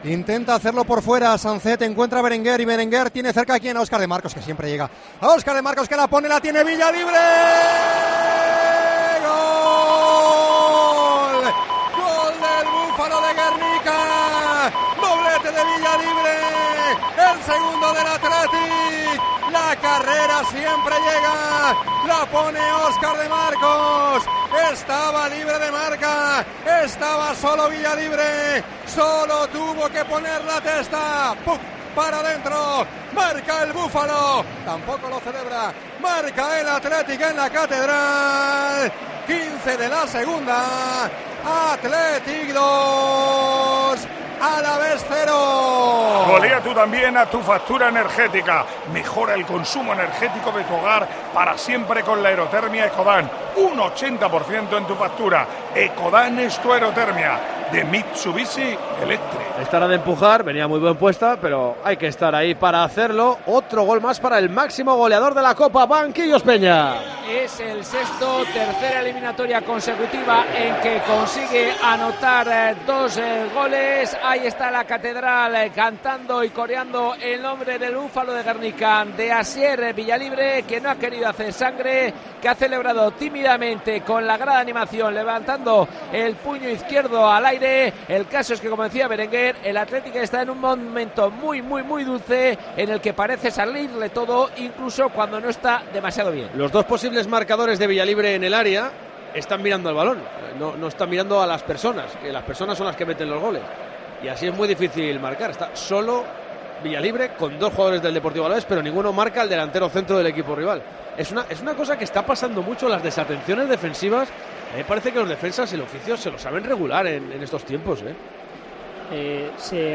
narra en Tiempo de Juego el segundo gol de Asier Villalibre